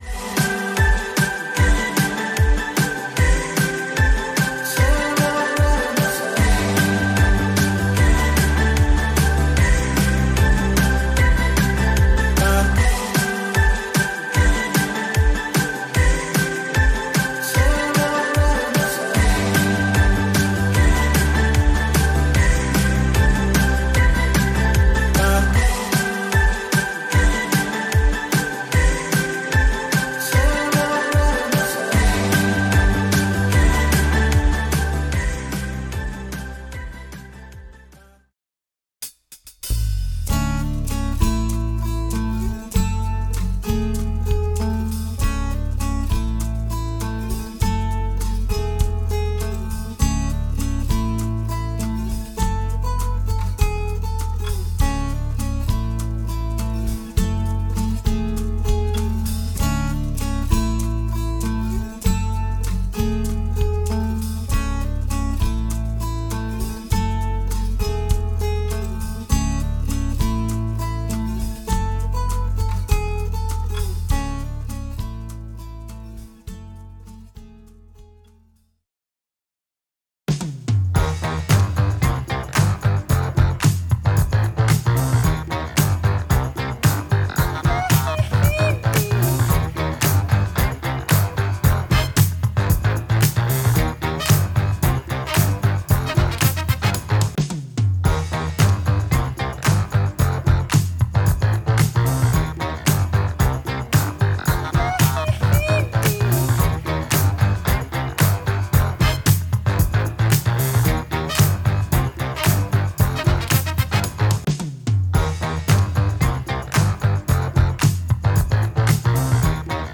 Hook-riff-break-quiz.m4a